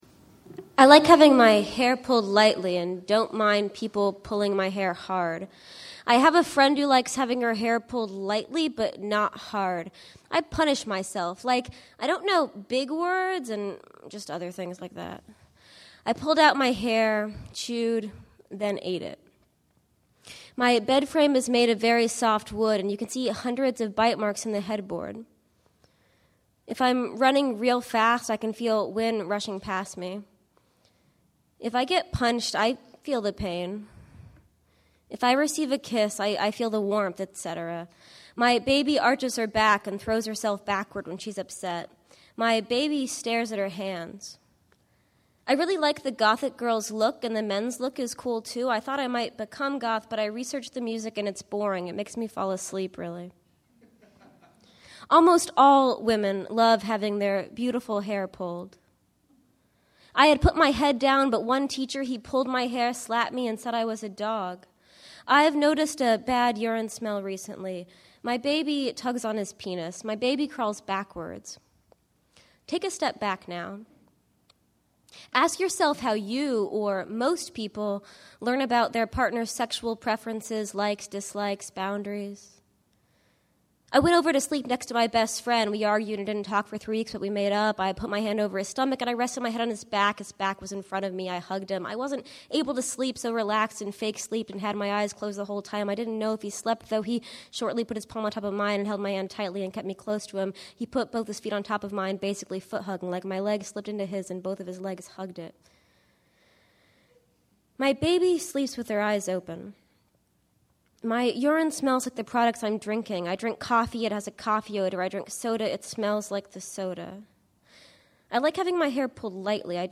The Recluse 7 Reading 5/20/11